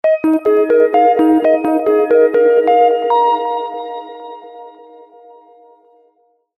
SNS（ソーシャル・ネットワーキング・サービス）に通知音にぴったりな長さの音。